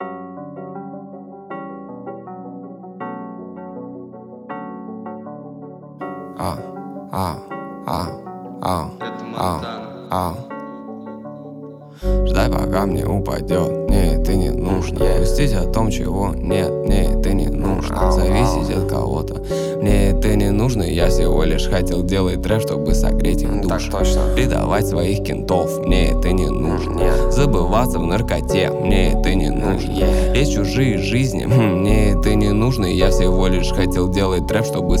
# Хип-хоп